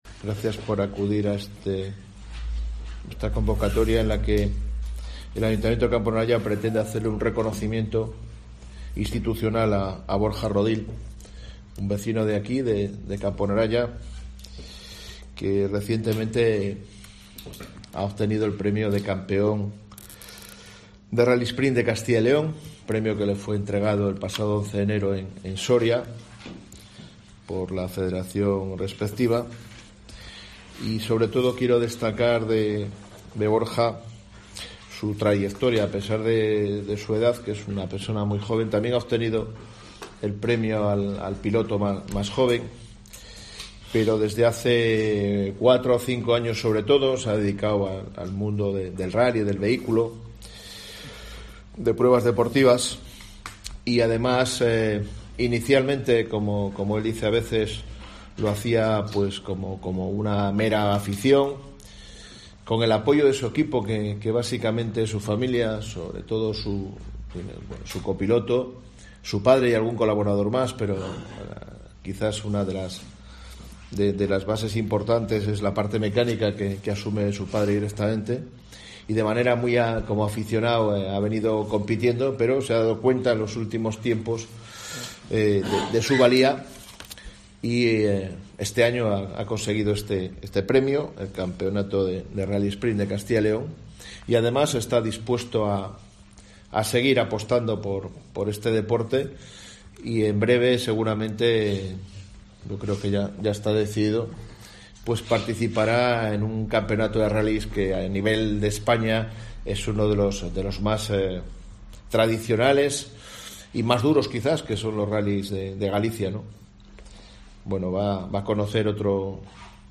El alcalde de Camponaraya, Eduardo Morán, le ha hecho entrega al deportista de una placa conmemorativa y ha puesto de relieve su trayectoria.